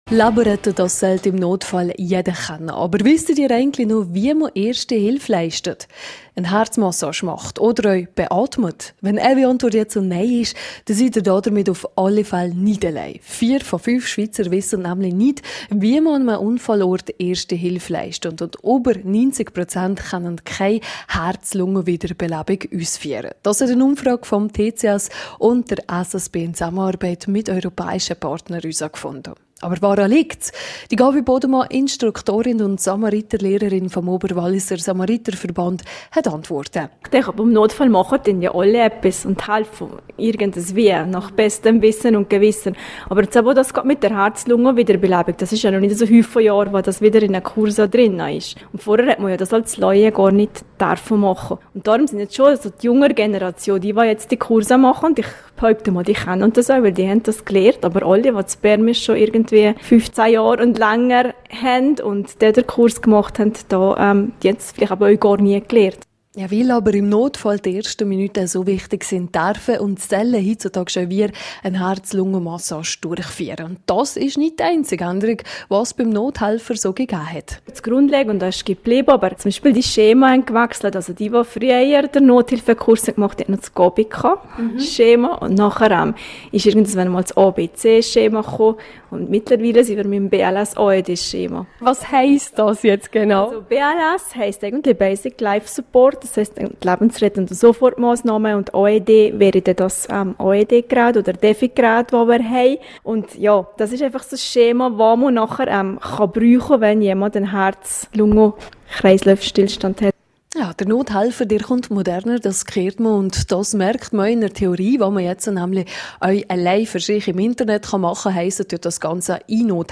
Hinzu komme, dass man die Herz-Lungen-Wiederbelebung früher als Laie nicht ausführen durfte./rj Audiobeitrag zum Thema (Quelle: rro) Weitere Infos zum Thema